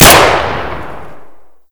shoot_3.ogg